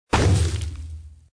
fire.mp3